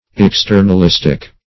externalistic - definition of externalistic - synonyms, pronunciation, spelling from Free Dictionary
Search Result for " externalistic" : The Collaborative International Dictionary of English v.0.48: Externalistic \Ex*ter`nal*is"tic\, a. Pertaining to externalism.